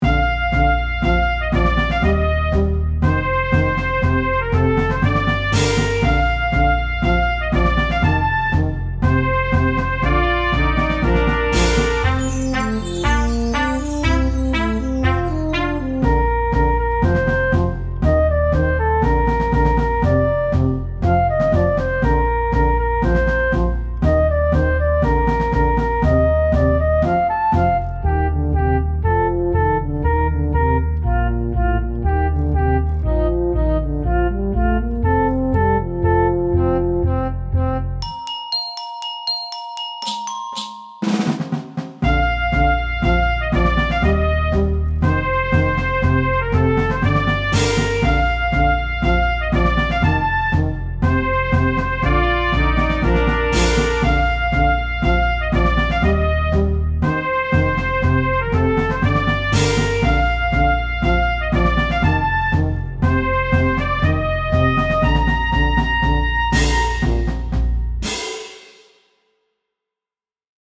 BGM
ショート明るい